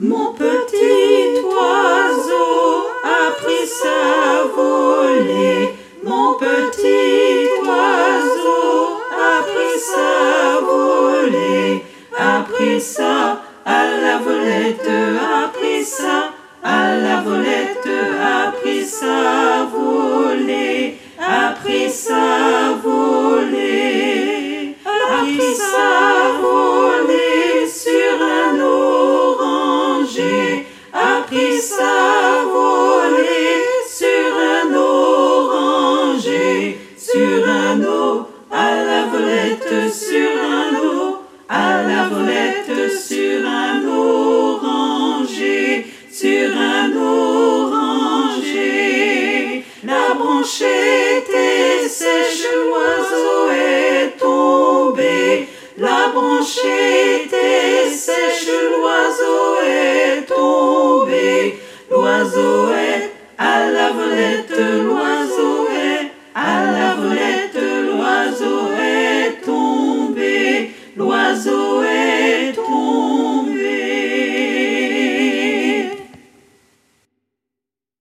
Toutes les voix